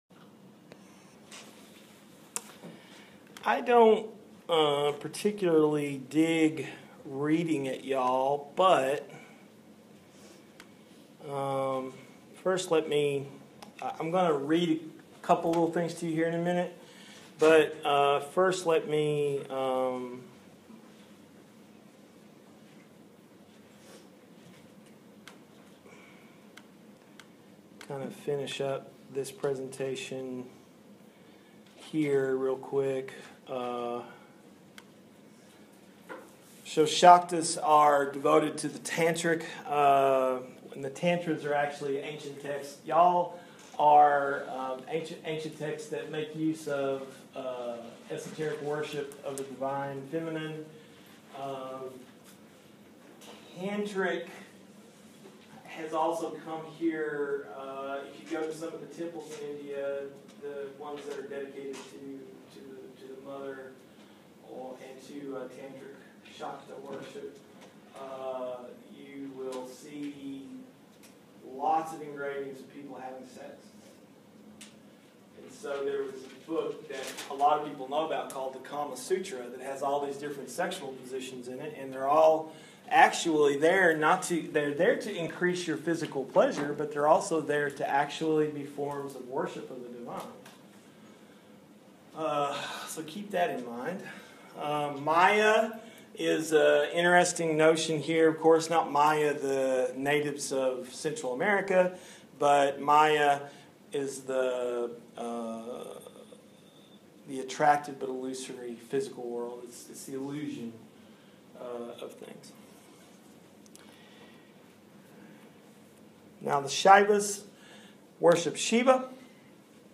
Because we had roamed around in and out of a few topics yesterday, I decided to mostly lecture today with the hope that we would catch up a bit but that there would still be a lot of good questions from my friends.